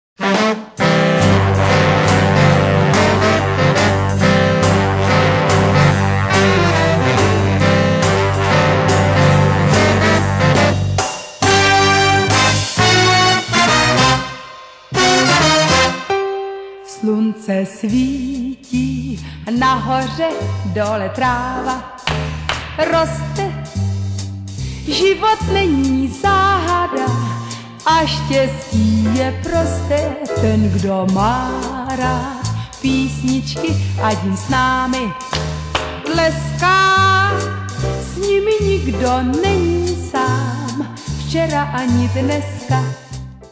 jazz woman singer